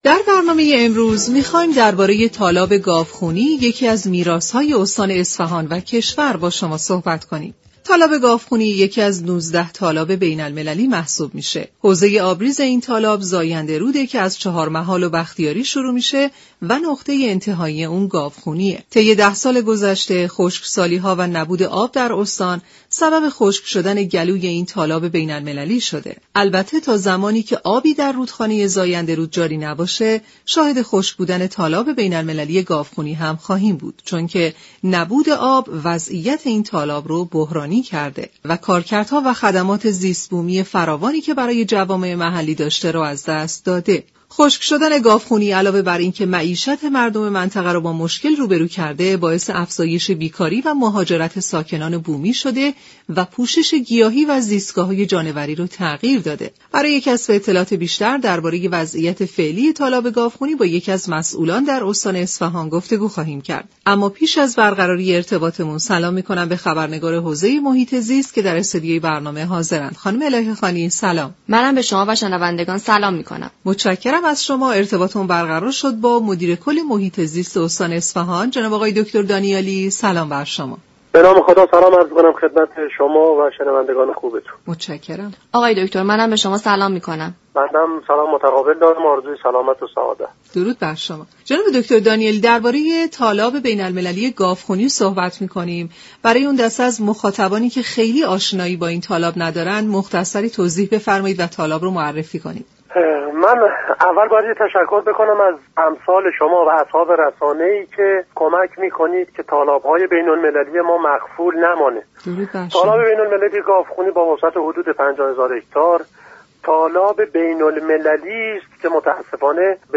مدیر كل محیط زیست استان اصفهان در گفت و گو با رادیو ایران گفت.